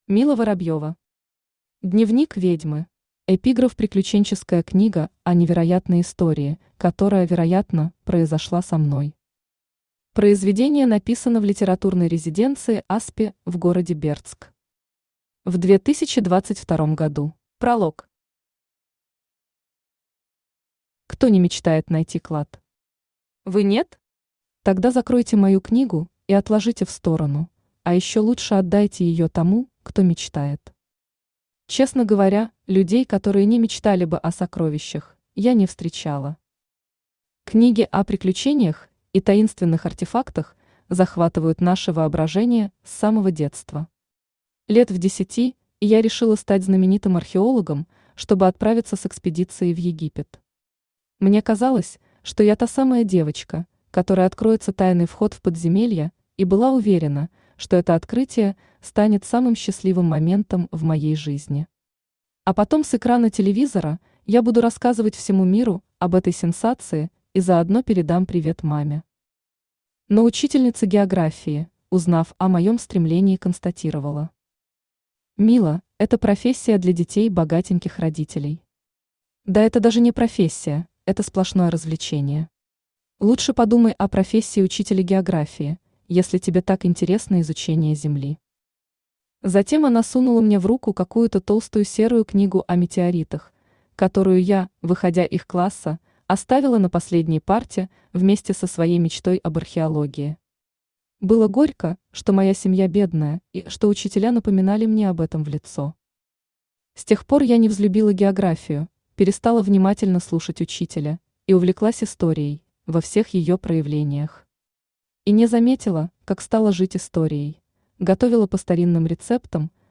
Аудиокнига Дневник ведьмы | Библиотека аудиокниг
Читает аудиокнигу Авточтец ЛитРес